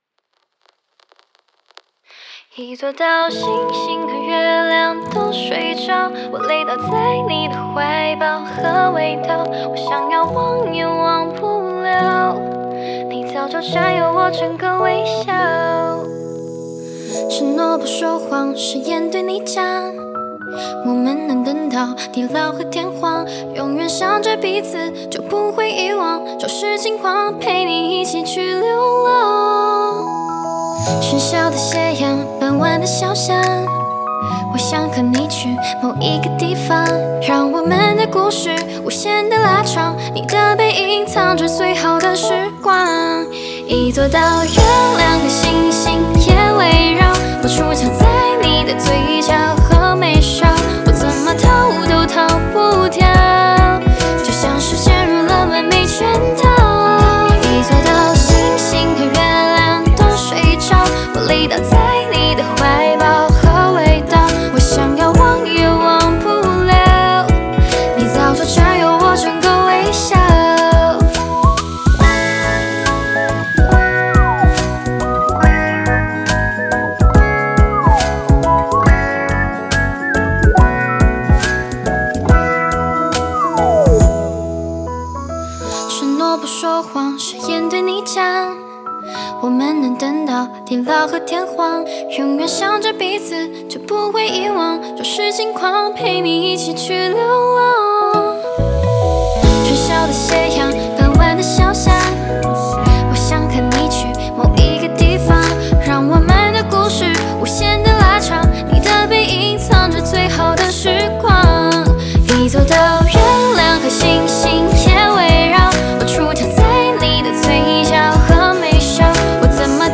吉他
和声